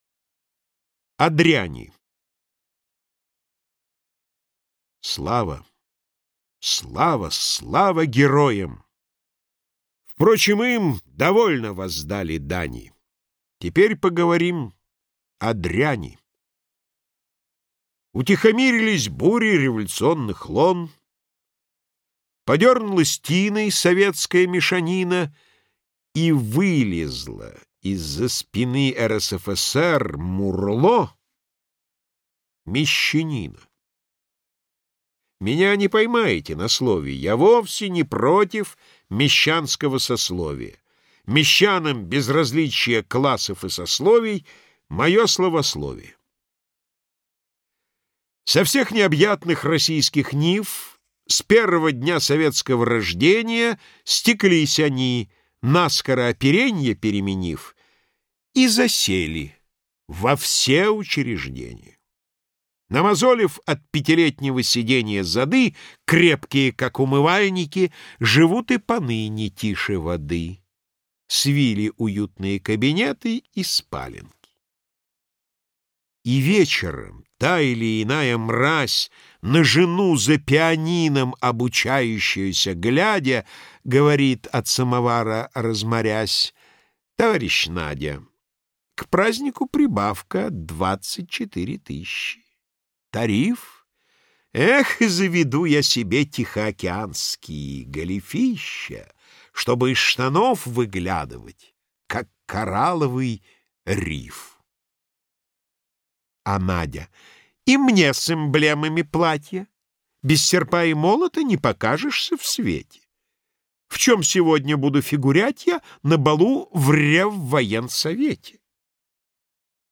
Прослушивание аудиозаписи стихотворения «О дряни» с сайта «Старое радио». Исполнитель Е. Киндинов.